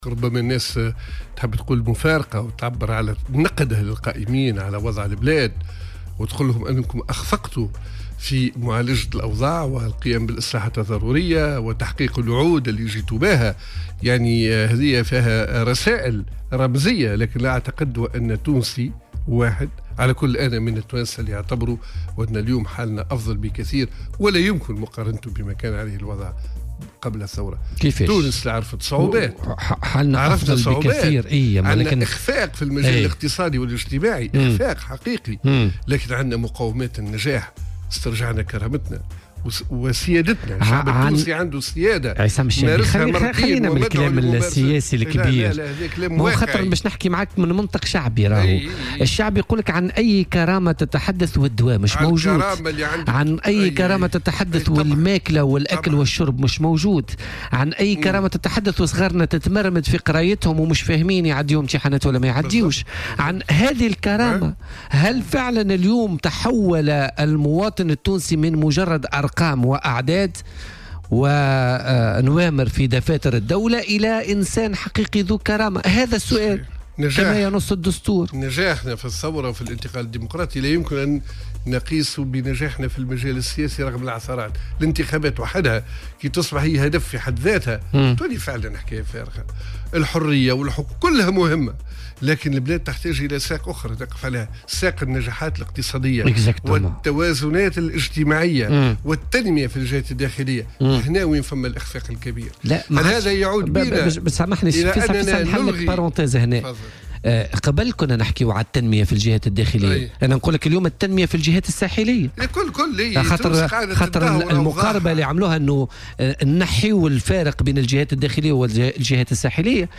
واستدرك ضيف "بوليتيكا" على "الجوهرة أف أم" بالقول إن هناك اخفاقا في المجال الاقتصادي والاجتماعي لكن هناك أيضا مقوّمات النجاح باسترجاع "كرامتنا وسيادتنا"، وفق ترجيحه، مشيرا في هذا الصدد الى أن الاهتمام بالمصالح السياسية على حساب مصالح المواطنين أدى الى هذه الاخفاقات الاقتصادية والاجتماعية.